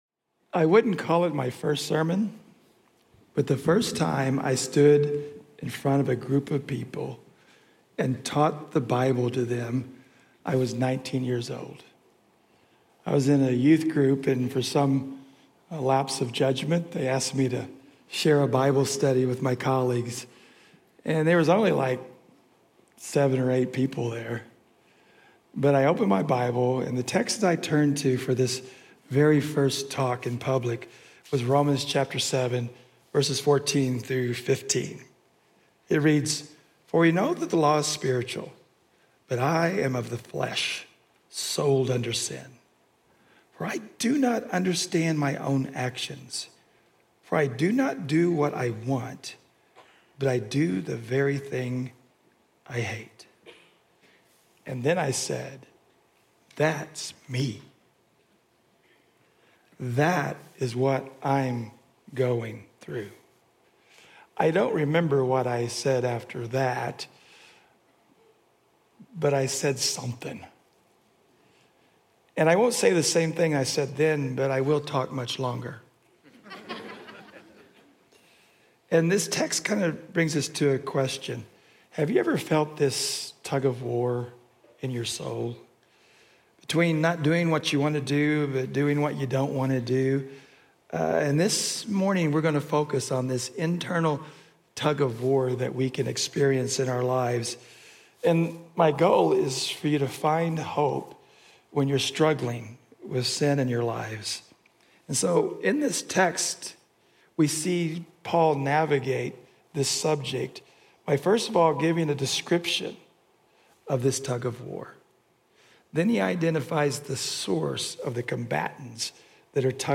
Journey Church Bozeman Sermons